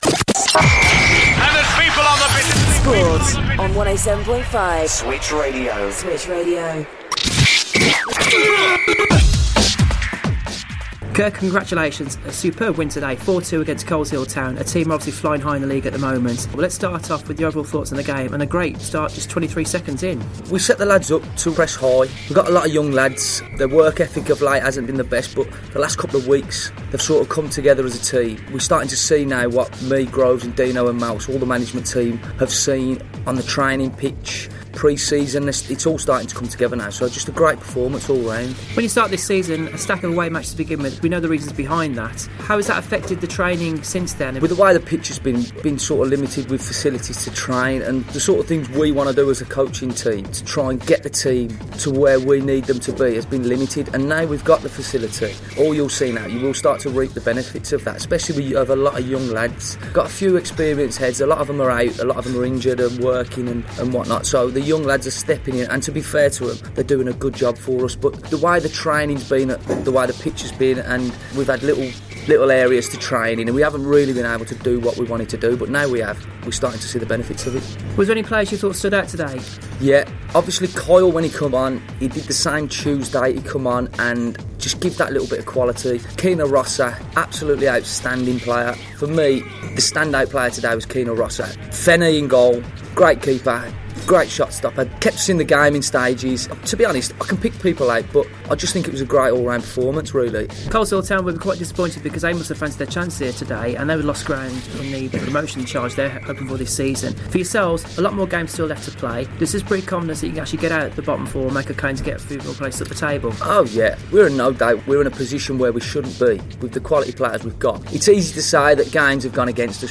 Post match reaction